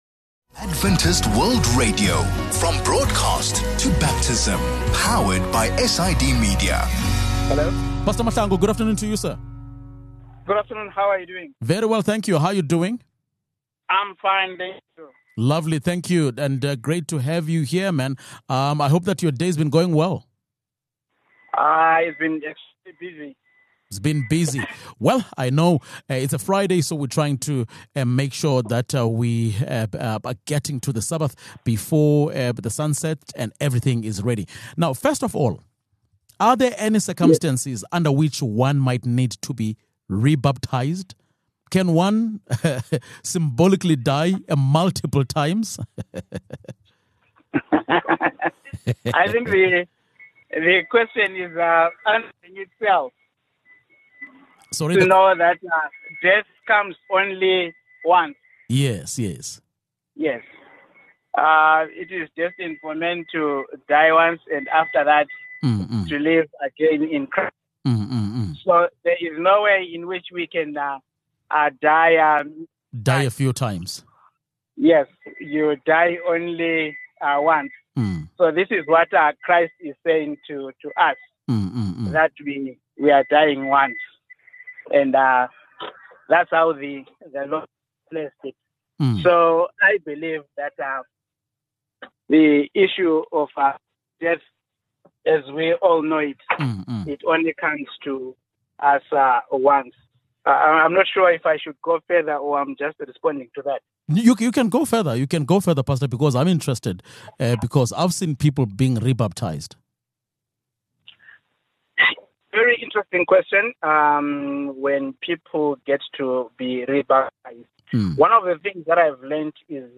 We conclude our study into baptism with a conversation on whether or not there are prerequisites for baptism.